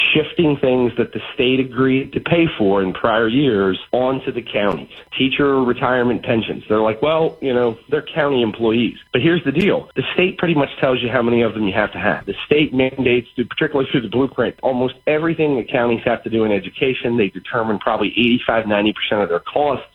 House Minority Leader Jason Buckel said these new mandates leave little for counties to do but raise taxes…